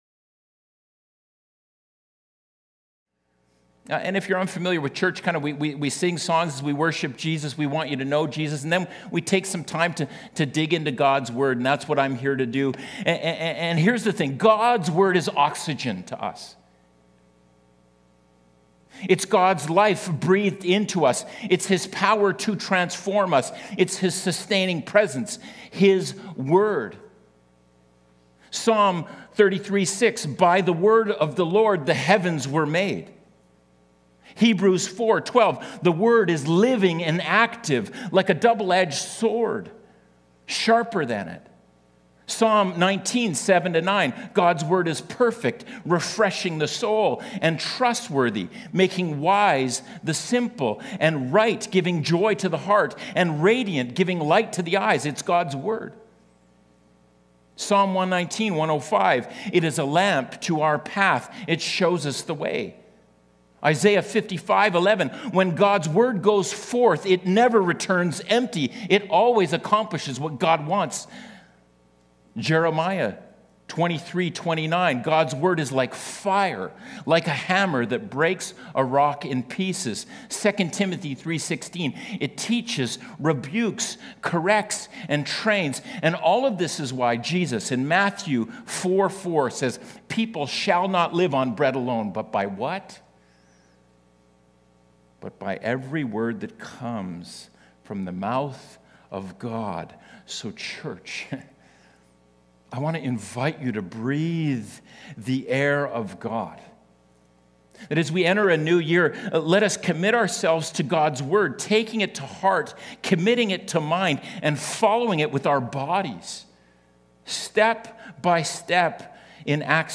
Hear the latest sermons from Mission Creek Alliance Church in Kelowna, BC, Canada.